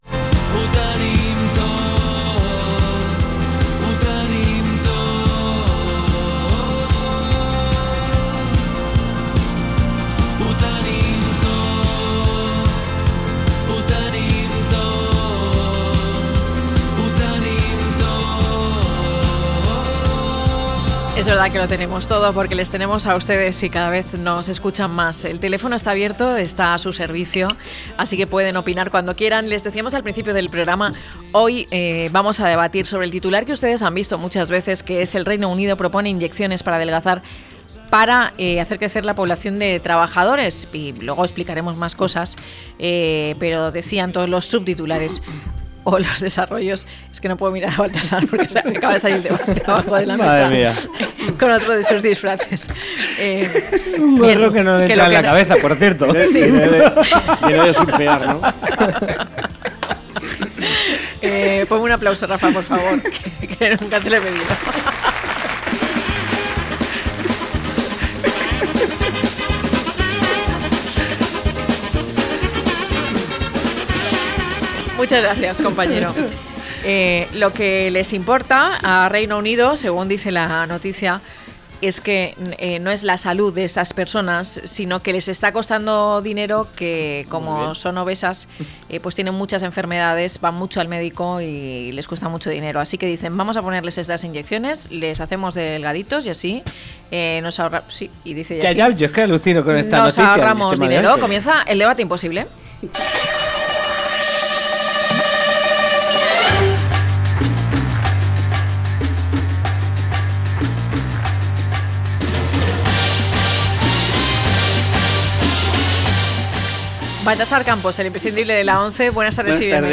1017-LTCM-DEBATE.mp3